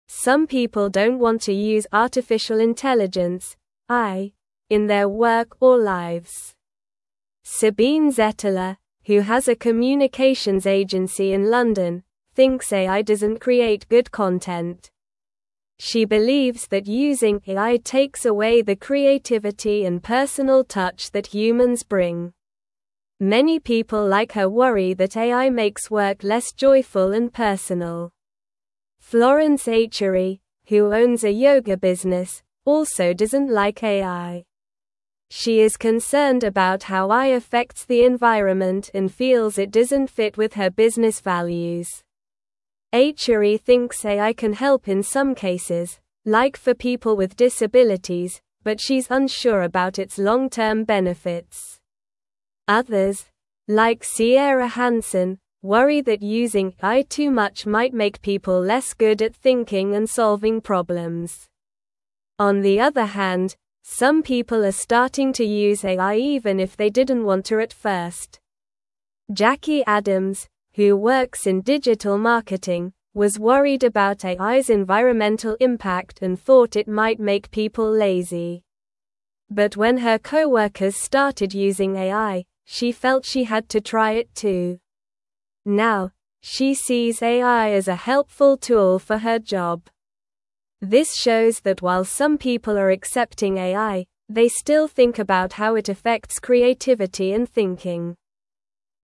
Slow
English-Newsroom-Lower-Intermediate-SLOW-Reading-People-Worry-About-AI-and-Creativity-and-Connection.mp3